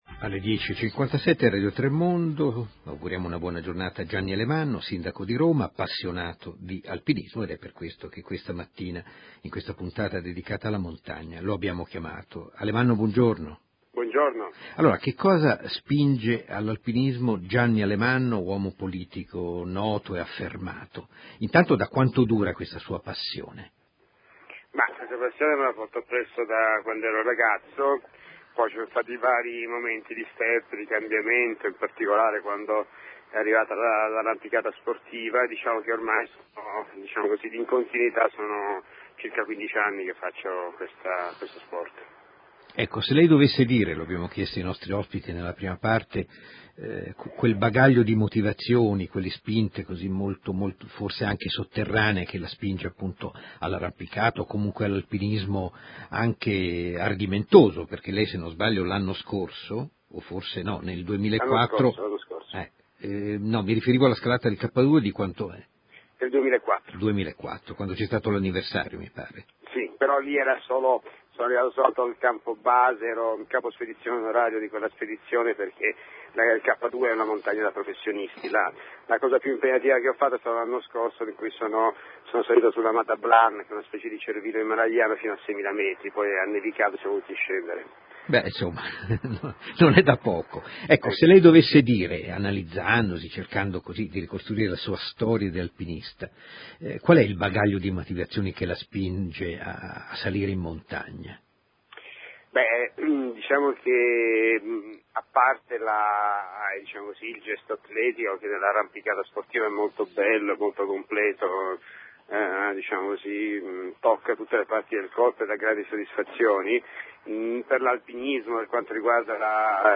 Intervista a Radio 3 Mondo